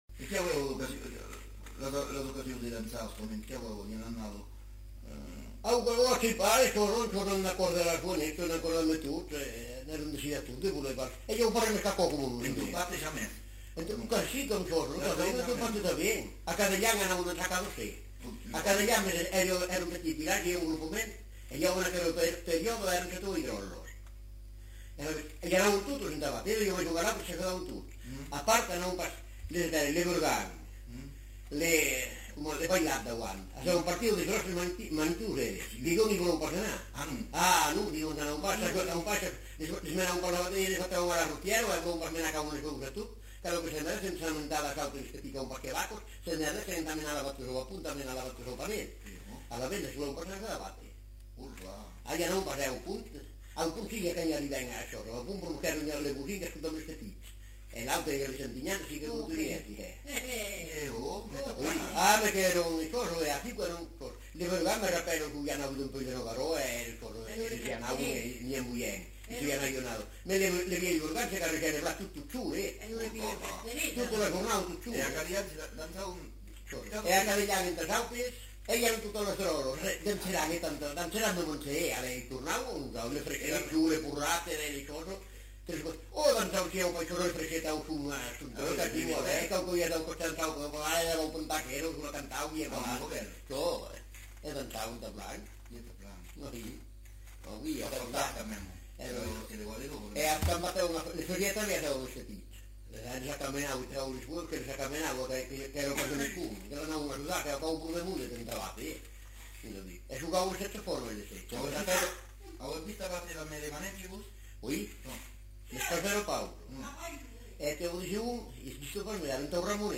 Lieu : Espaon
Genre : témoignage thématique